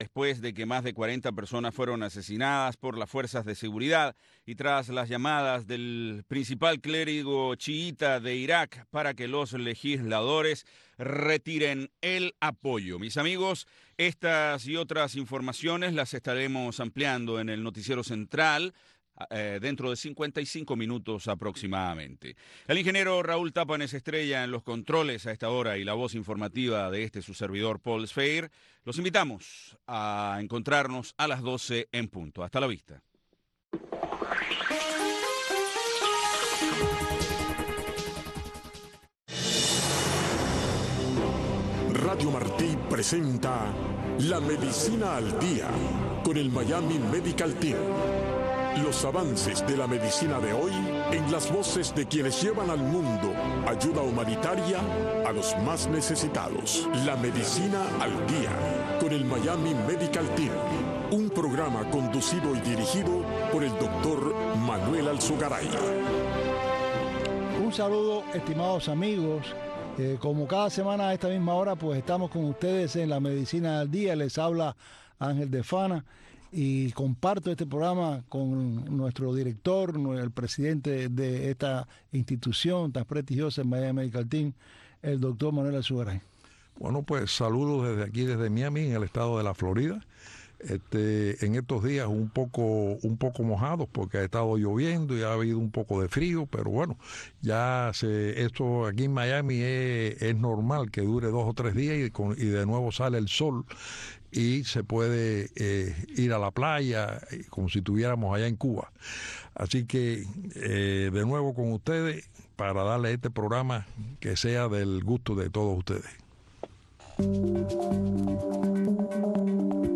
Un programa concebido para que conozcas los avances de la medicina en el mundo de hoy, en la voz de consagrados galenos y diversos profesionales del mundo de la medicina.